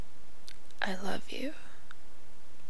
challenge crying drama request sobbing speech voice sound effect free sound royalty free Memes